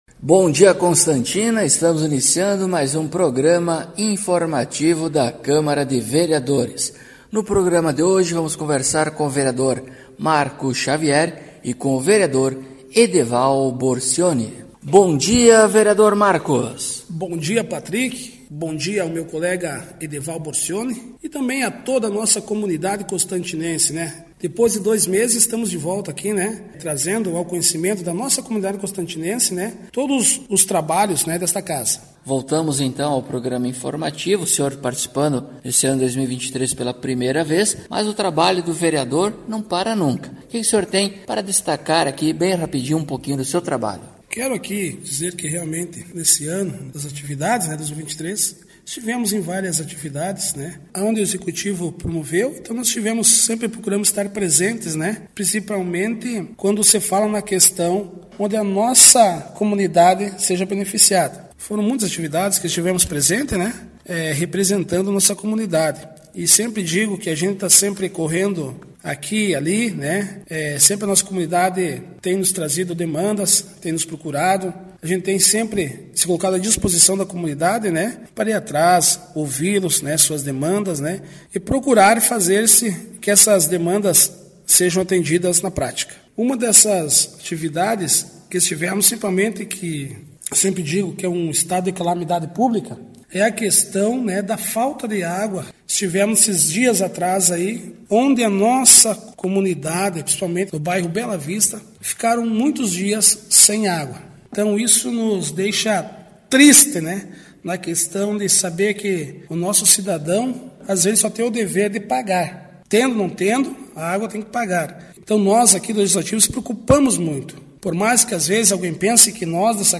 Acompanhe o programa informativo da câmara de vereadores de constantina com o Vereador Marco Xavier e o Vereador Edeval Borcioni.